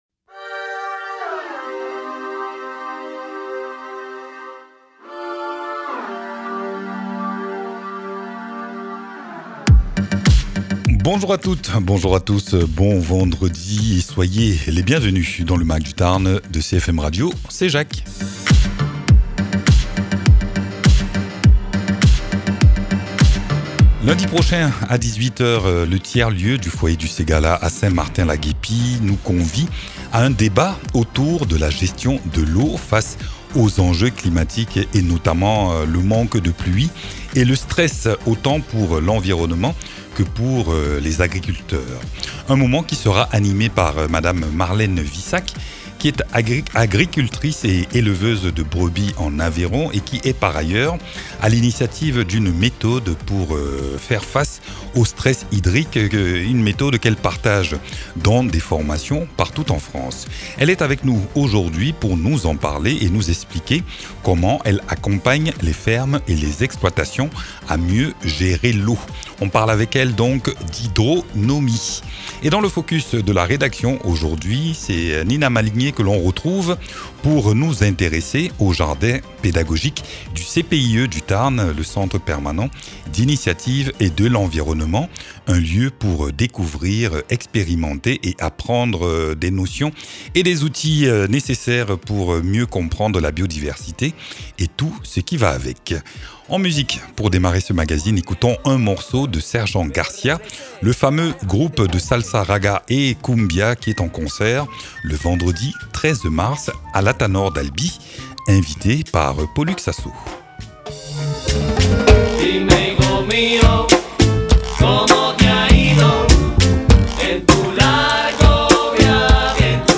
Mags
Egalement dans ce numéro, nous nous rendons au jardin pédagogique du CPIE du Tarn, lieu vivant d’expérimentation et de transmission.